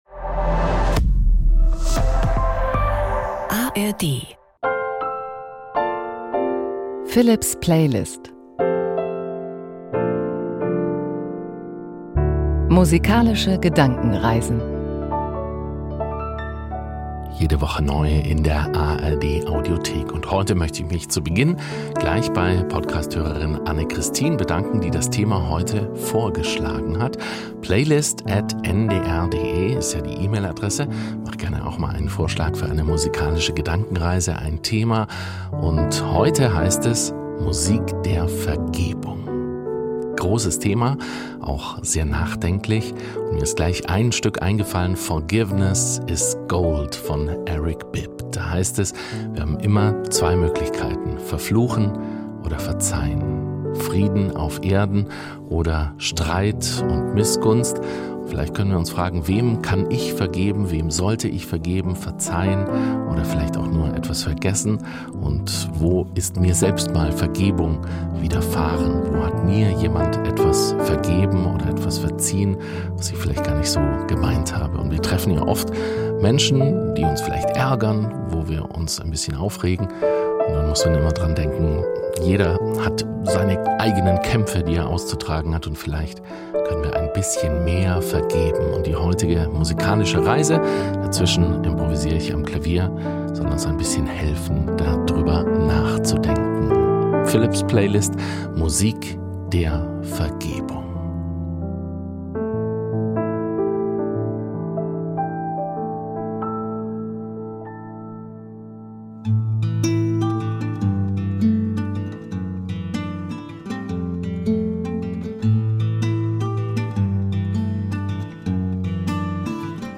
Musik zum Waldbaden. Klassisch in den Schlaf.
Von Pop bis Klassik – die Musik ist für ihn ein Anlass, sich gemeinsam mit seinen Hörerinnen und Hörern auf fantasievolle Gedankenreisen zu begeben.
Zu den einzelnen Stücken jeder Folge improvisiert er am Klavier.